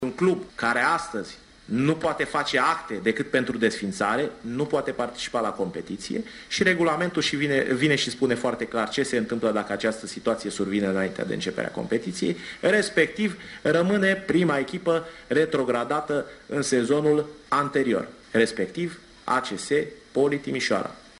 astfel încât azi într-o conferință de presă